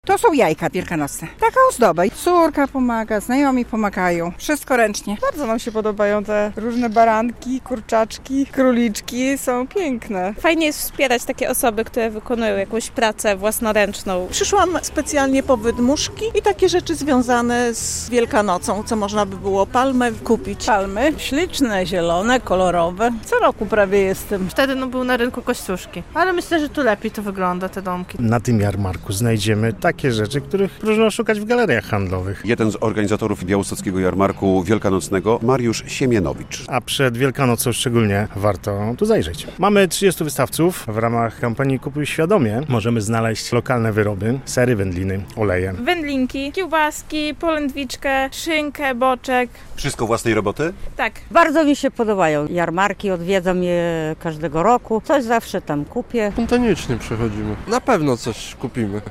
Jarmark Wielkanocny w Białymstoku